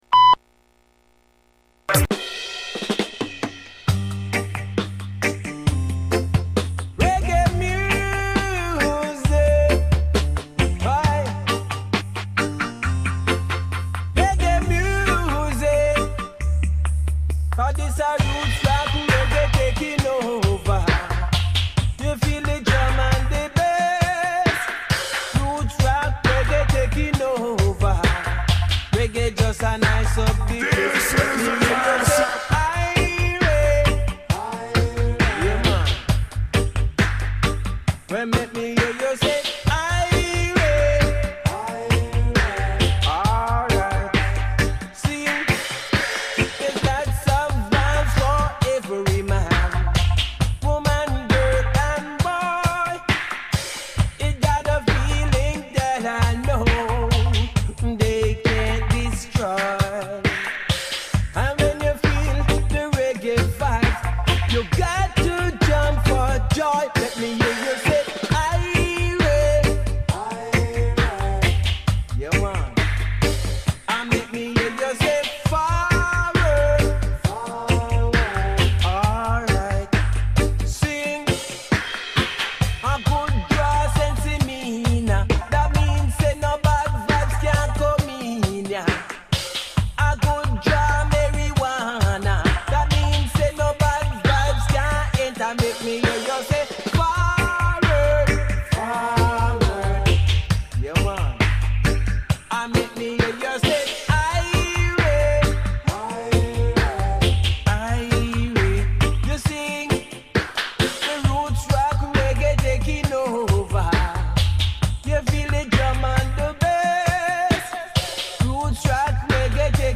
Guadamaica reggae & dubwise radio show
Guadamaica Programa de radio enfocado a la música reggae, su cultura y distintos géneros musicales, centrándonos en la vertiente denominada dub y todas sus ramificaciones, sin olvidarnos de la cultura & mundo Sound system.